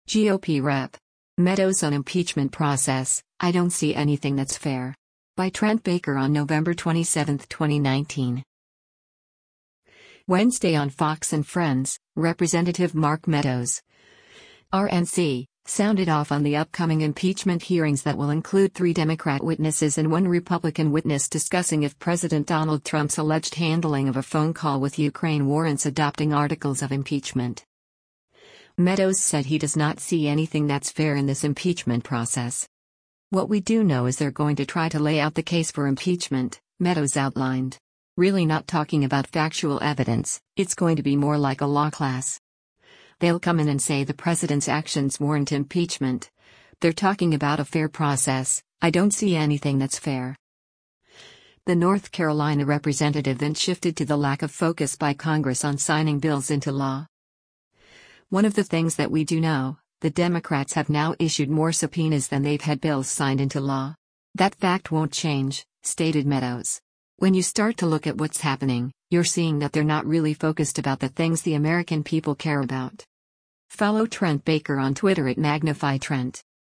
Wednesday on “Fox & Friends,” Rep. Mark Meadows (R-NC) sounded off on the upcoming impeachment hearings that will include three Democrat witnesses and one Republican witness discussing if President Donald Trump’s alleged handling of a phone call with Ukraine warrants adopting articles of impeachment.